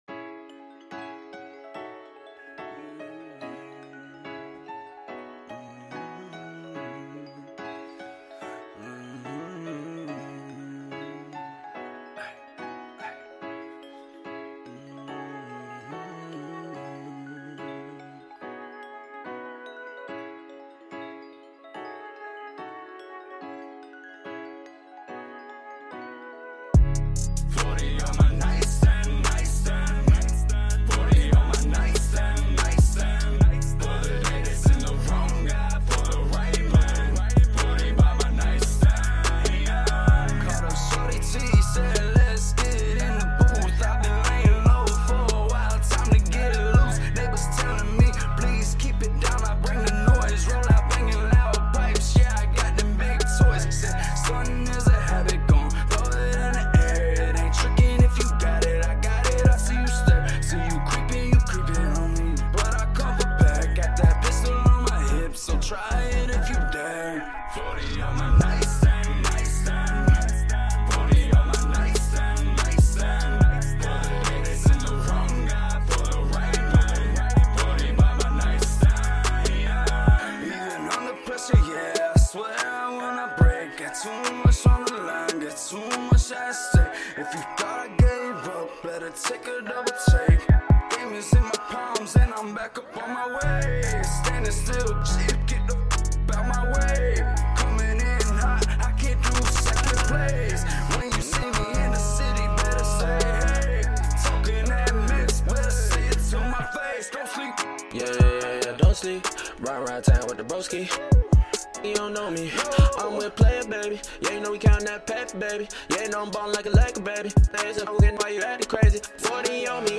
American Country Hip-Hop Rocker